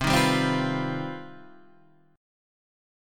C Minor Major 11th